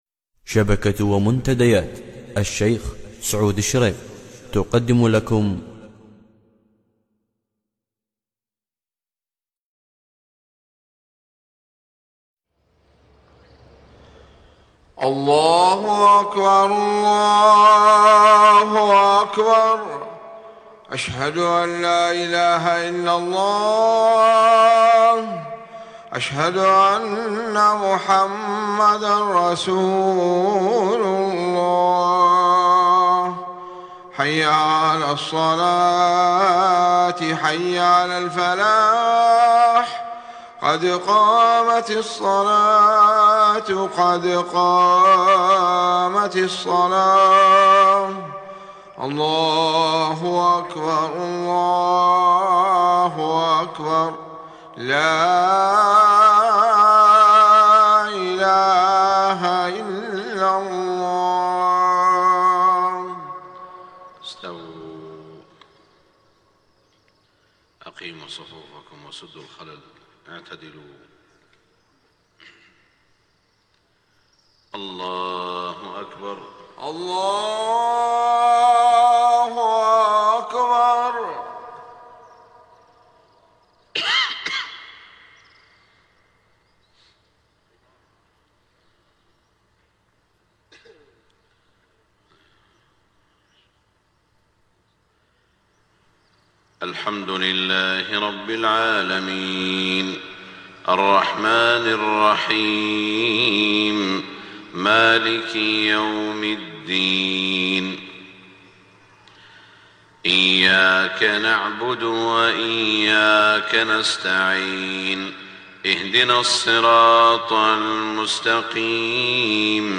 صلاة الفجر 2-3-1428هـ سورتي الفجر و البلد > 1428 🕋 > الفروض - تلاوات الحرمين